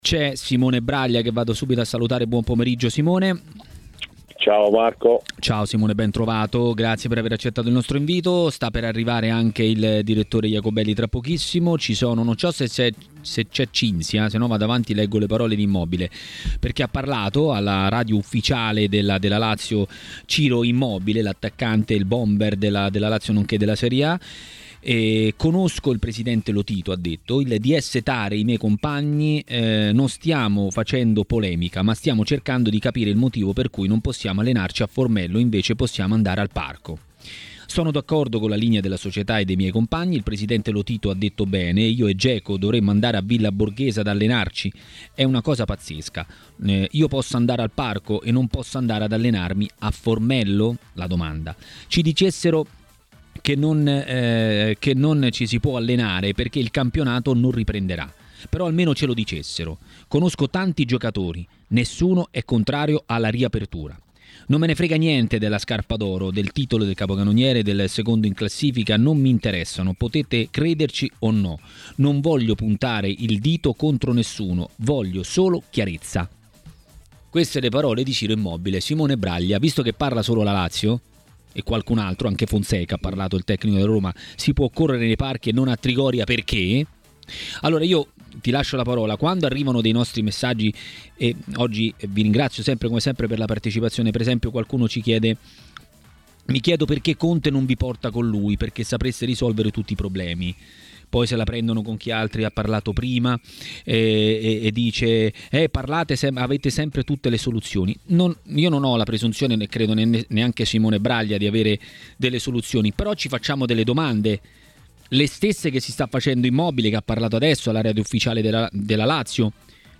Per parlare della possibile ripresa del campionato di Serie A è intervenuto a TMW Radio, durante Maracanà, mister Gigi Cagni.